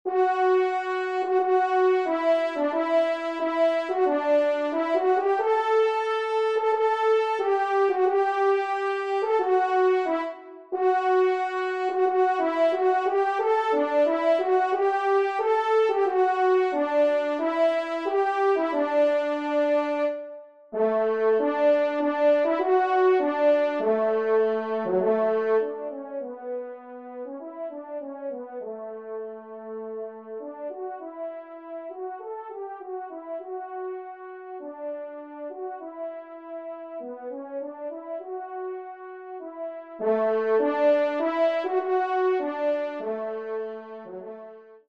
Pupitre 2° Cor (en exergue)